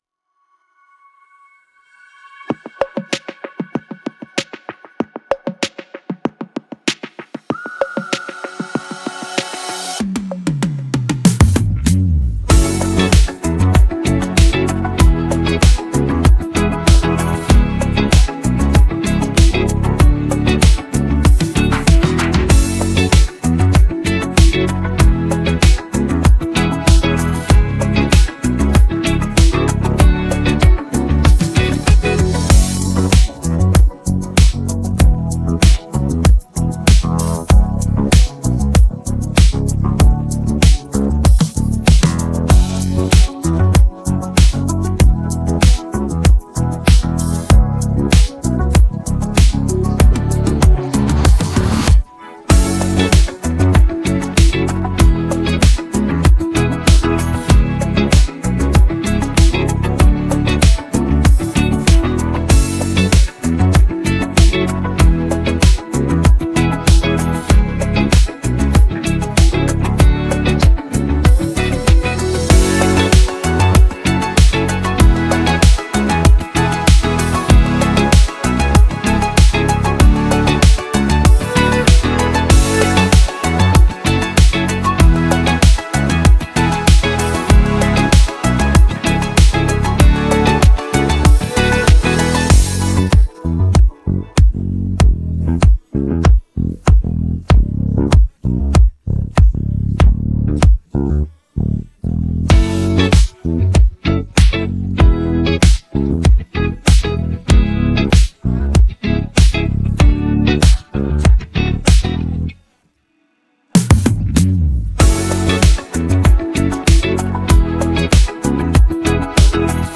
بیت بدون صدای خواننده